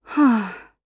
Glenwys-Vox_Sigh_jp.wav